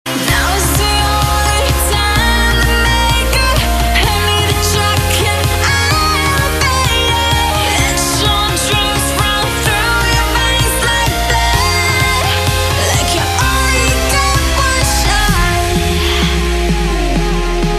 M4R铃声, MP3铃声, 欧美歌曲 67 首发日期：2018-05-13 12:35 星期日